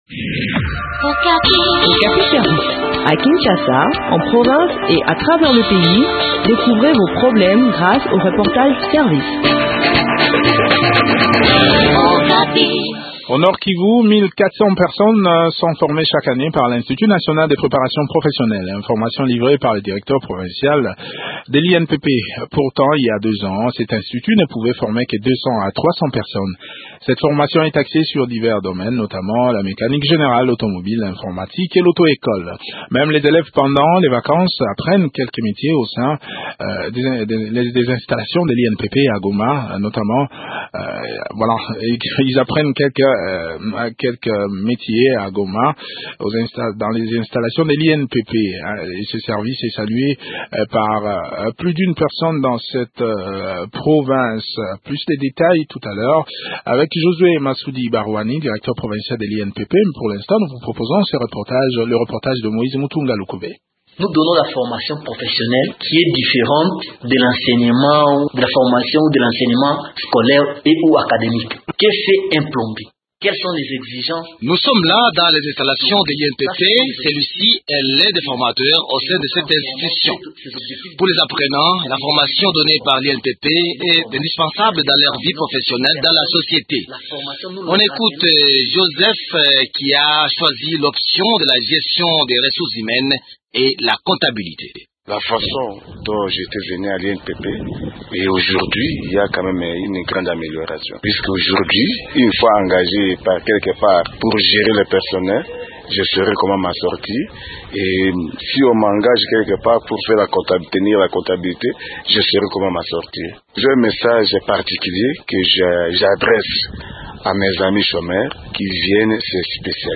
Le point sur l’organisation des différentes filières de formation dans cet entretien